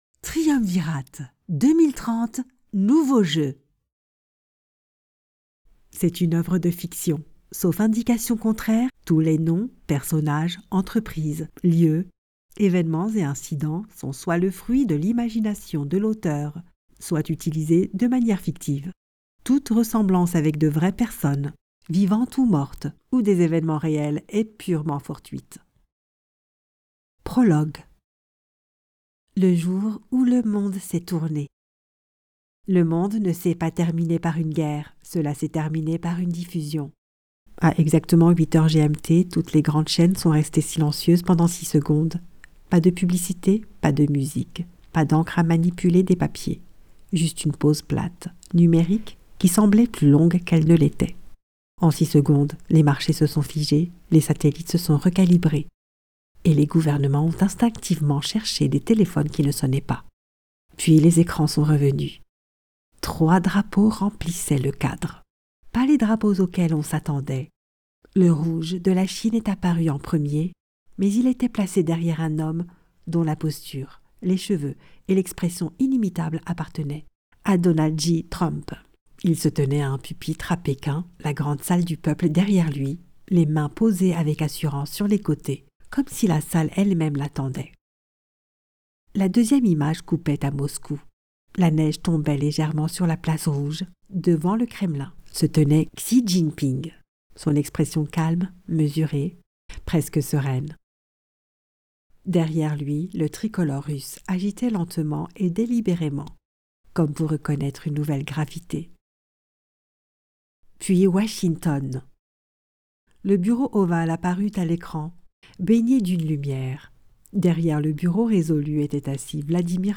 Female Voice Over Talent, Artists & Actors
Yng Adult (18-29) | Adult (30-50)